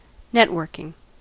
networking: Wikimedia Commons US English Pronunciations
En-us-networking.WAV